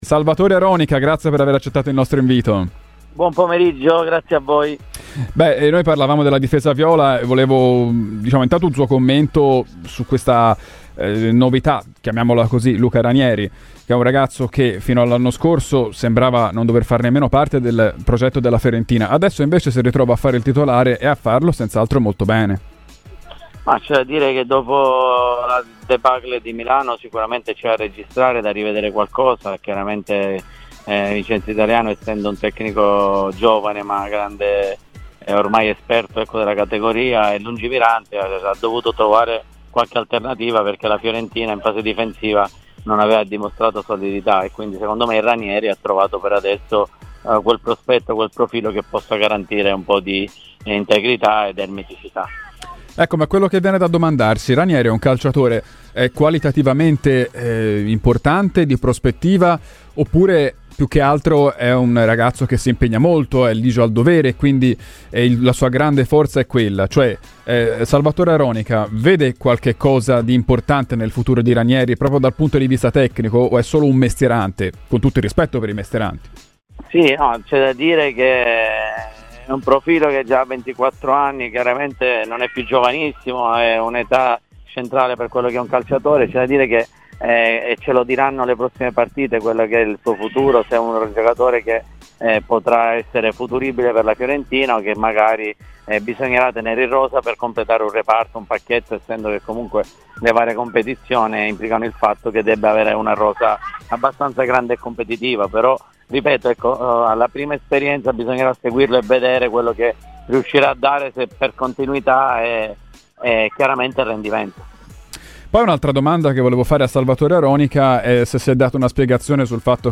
L'ex difensore tra le altre anche del Napoli, Salvatore Aronica, ha parlato in esclusiva a Radio Firenzeviola durante la trasmissione "Viola Weekend".